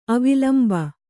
♪ avilamba